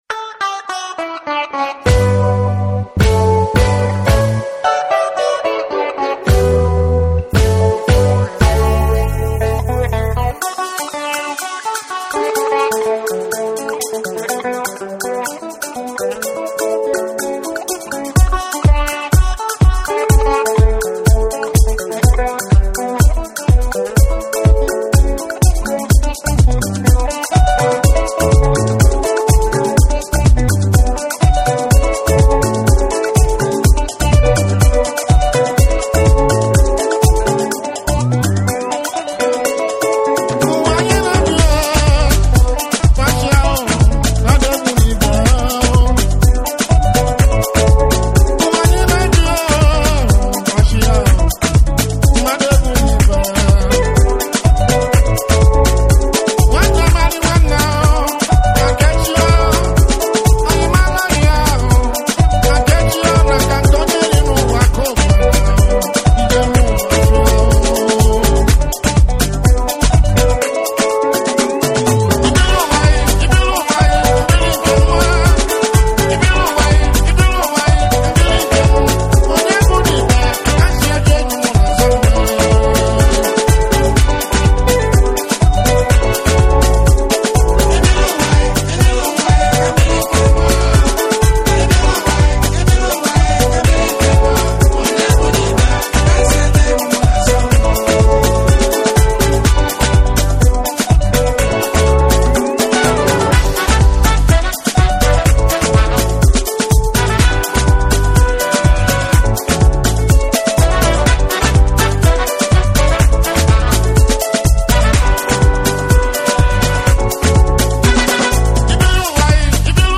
一足早く全曲フルで聴きましたが、終始流れるハッピーなヴァイブス。原曲の魅力を最大限に引き出すコンテンポラリーな電子化。
WORLD / BREAKBEATS / NEW RELEASE(新譜)